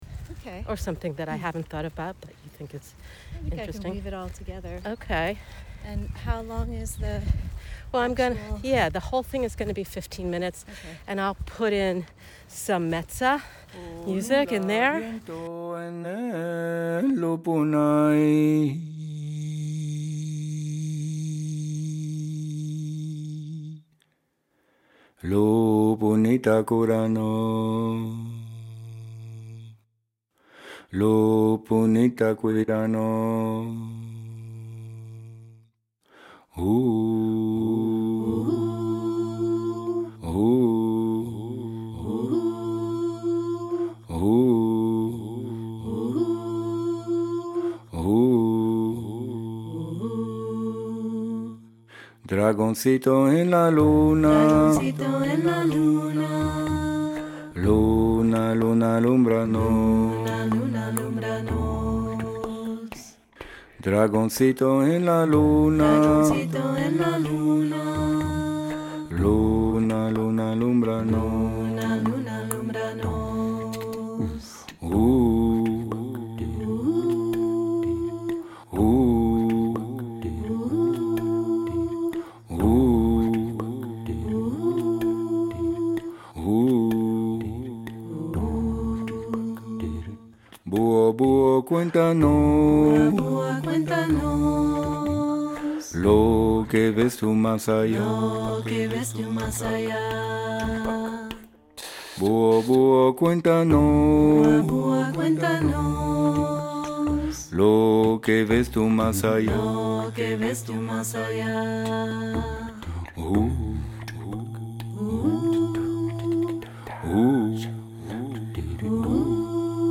is an interview-based segment that is a mini-deep dive into the world of care, what we care about...and maybe why.